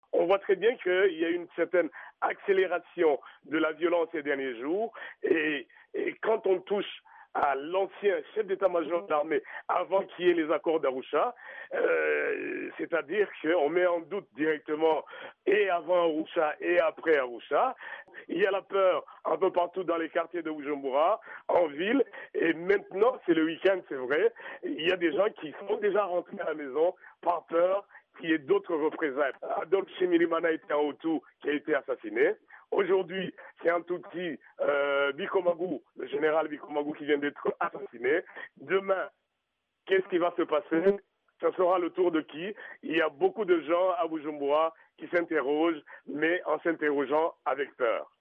Le point avec notre correspondant à Bujumbura.